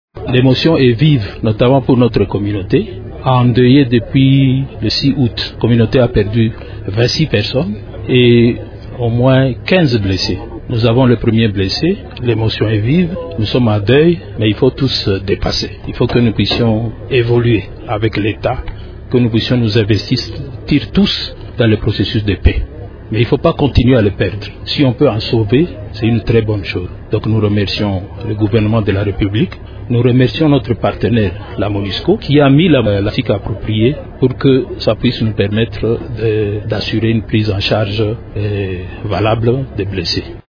A son tour, la communauté Lori remercie la MONUSCO et les autorités provinciales pour cette opération, qui, selon elle, va sauver les vies humaines. Elle réitère les vœux de voir les violences cesser et la paix gagner les cœurs et les esprits, au sein des communautés vivant en Ituri. Un de leur représentant s’est ainsi exprimé en ces termes :